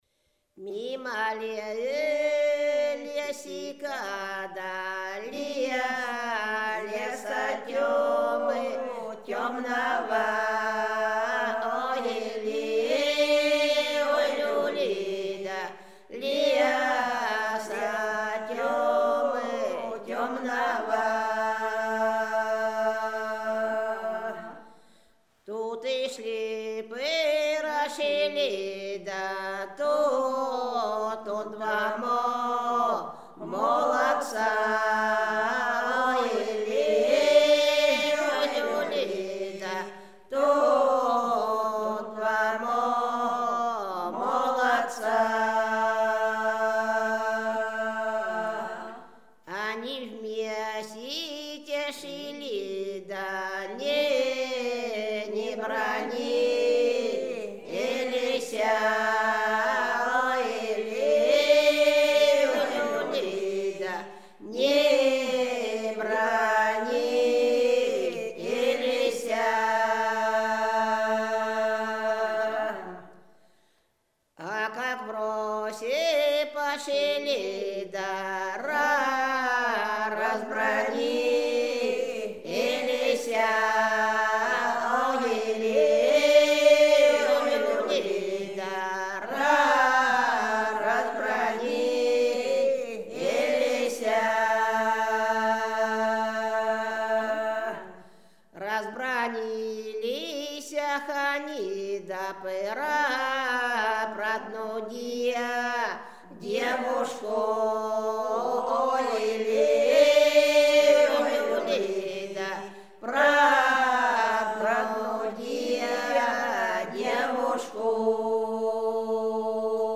Рязань Секирино «Мимо лесика», круговая.